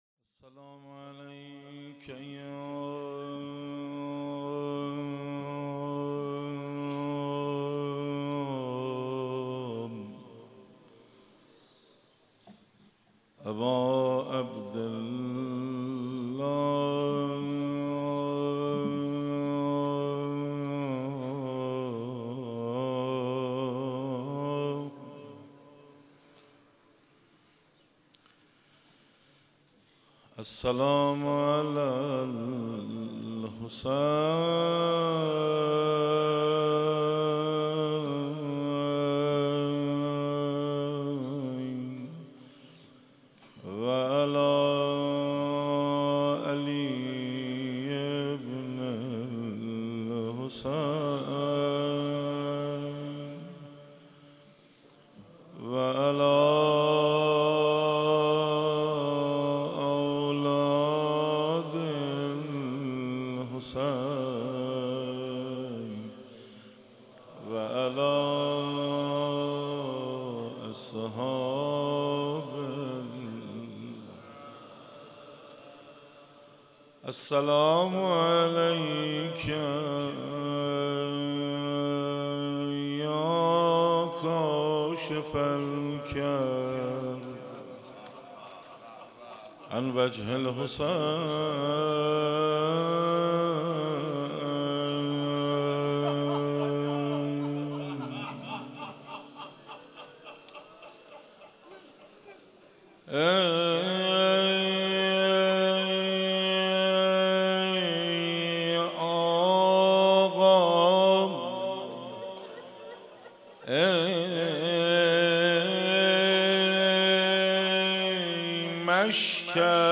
شب تاسوعا محرم 97 - کربلای معلی - روضه - ای مشکت آبروی تمامی آب ها